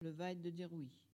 Collectif atelier de patois
Locution